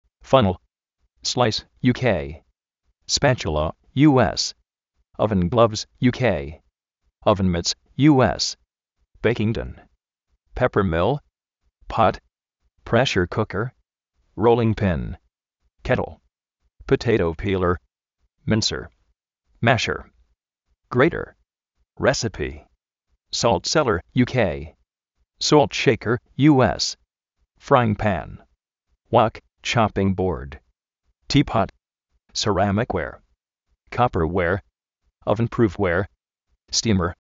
Vocabulario en ingles, diccionarios de ingles sonoros, con sonido, parlantes, curso de ingles gratis
fánel
sláis (UK)
spátiula (US)
présher-kúker
potéitou pí:ler